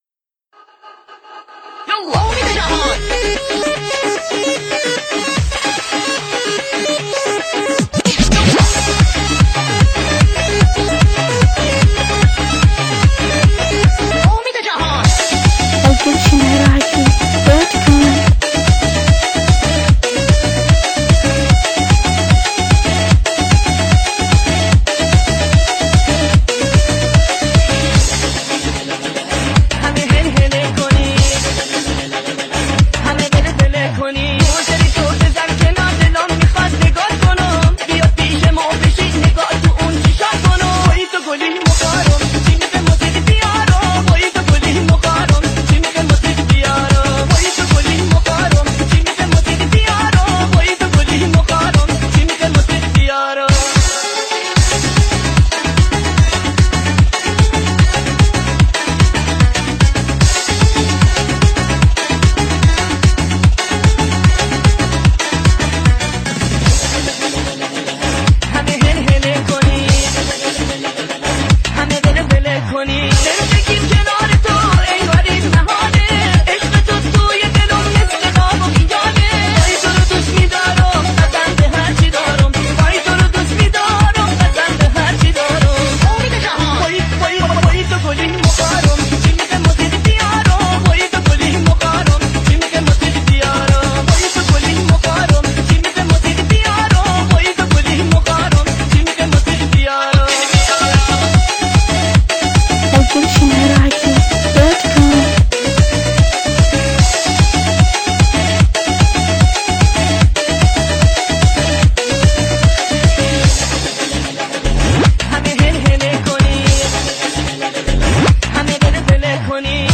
تسريع مميز